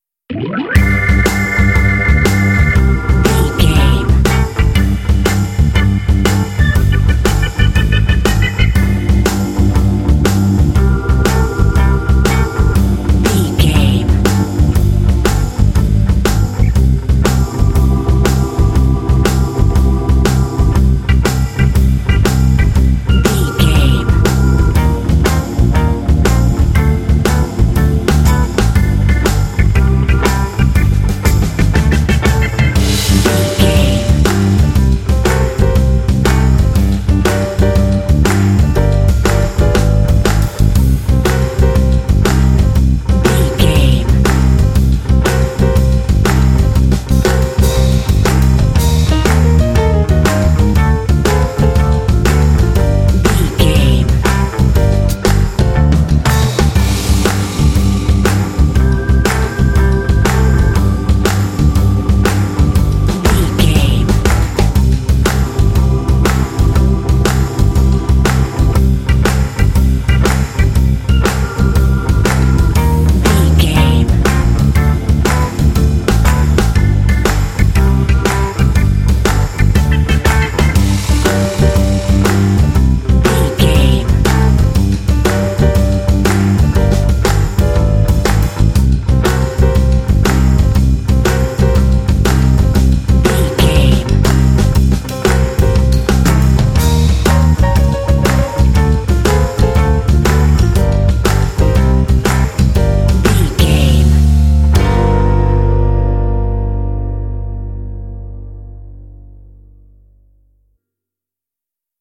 12-bar blues track
Aeolian/Minor
groovy
smooth
bass guitar
electric guitar
drums
electric organ
blues
jazz